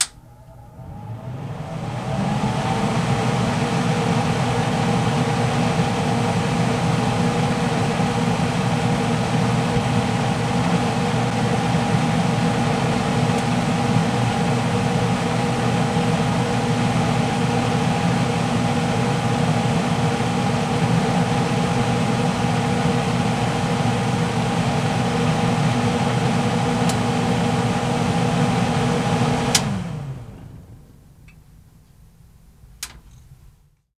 VACUUM CL00L.wav